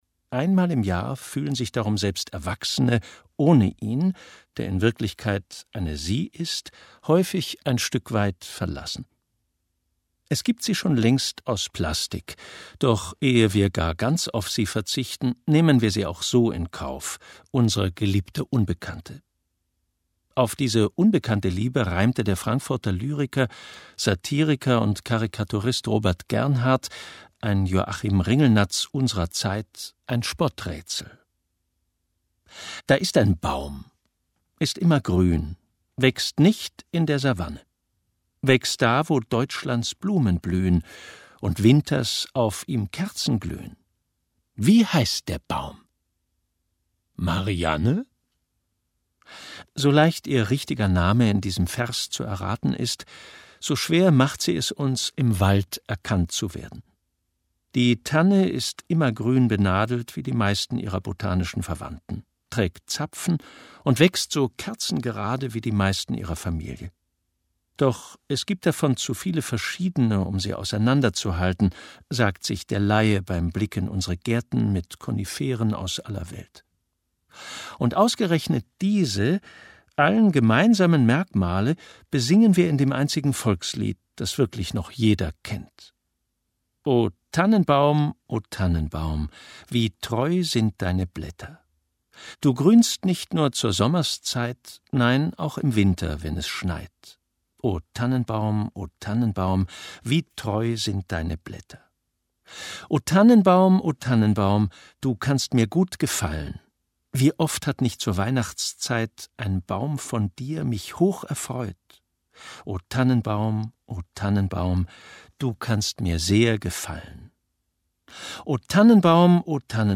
Ungekürzte Lesung mit Frank Arnold (3 CDs)
Frank Arnold (Sprecher)